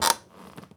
chair_frame_metal_creak_squeak_07.wav